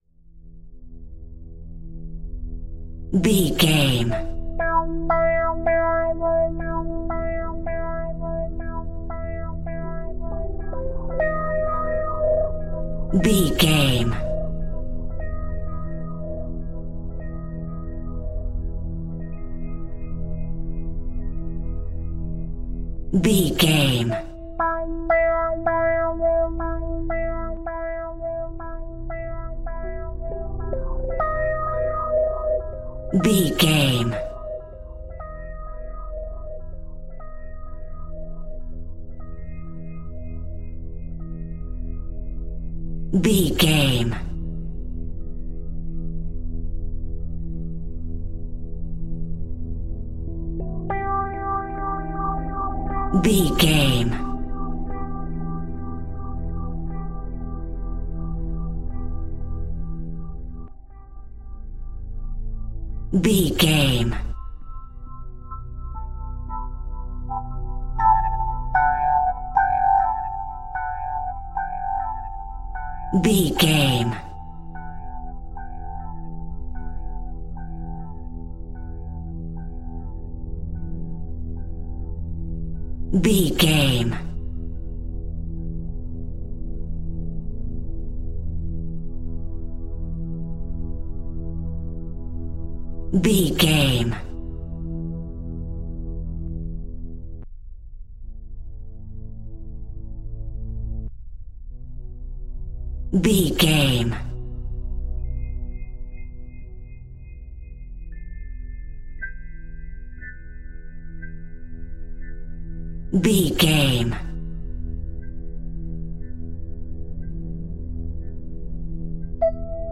Aeolian/Minor
Slow
scary
ominous
suspense
eerie
synthesiser
piano
horror
Synth Pads
Synth Strings
synth bass